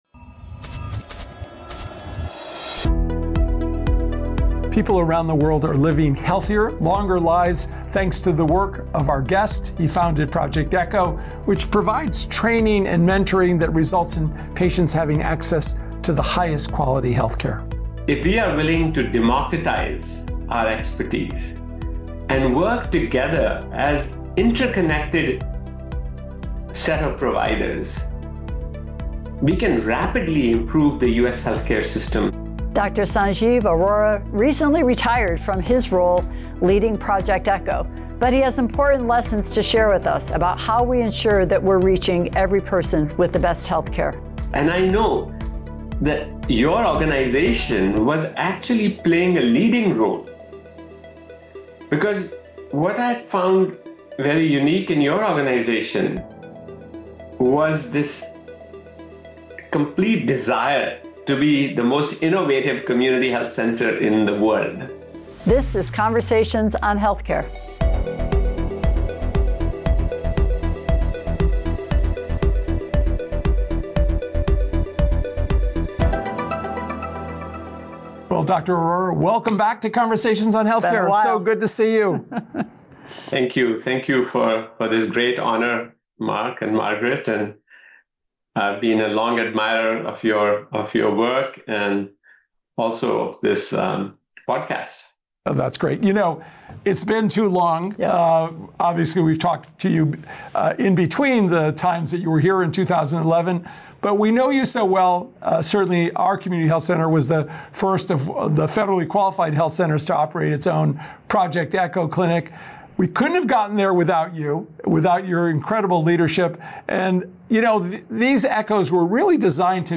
In this new interview